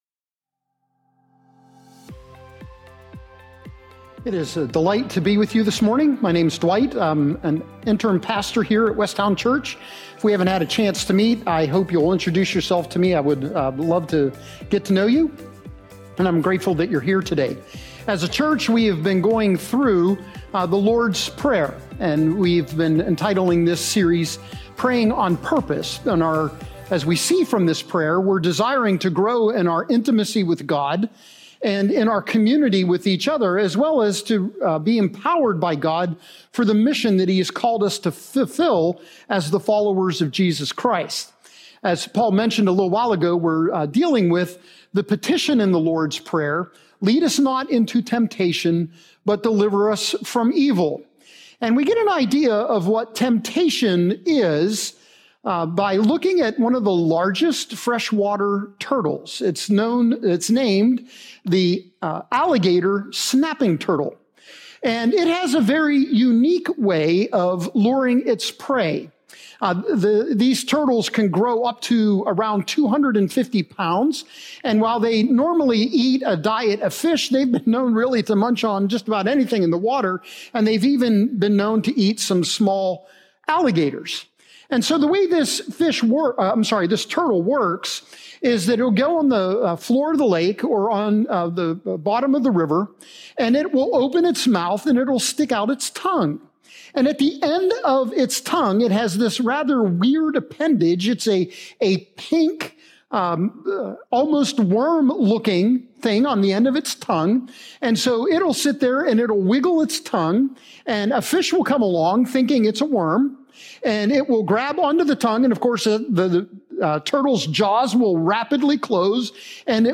This Sunday’s message is on temptation – Temptation comes at us in a variety of ways every day.